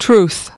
14. truth (n) /truːθ/: sự thật